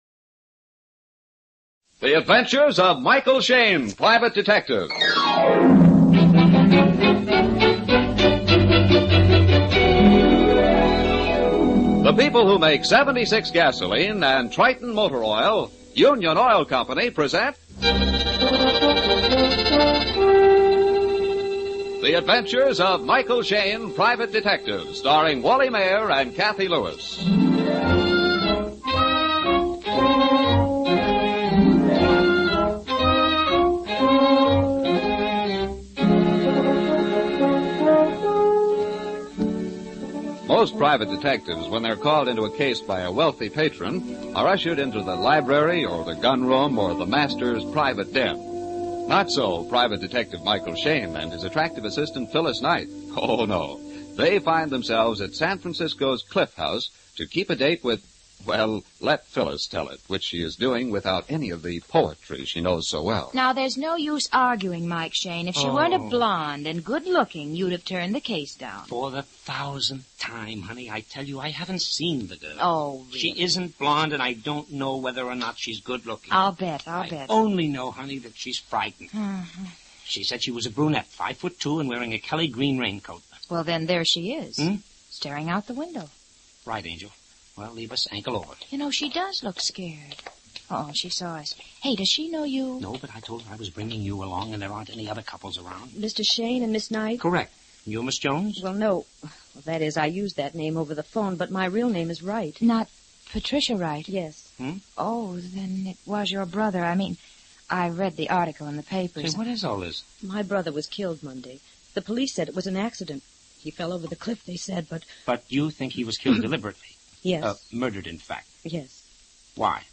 Michael Shayne 450416 Date At Cliff House, Old Time Radio